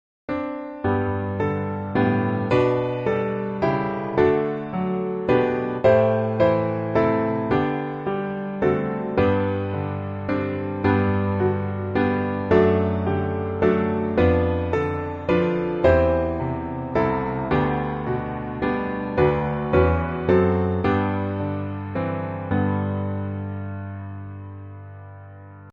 G Major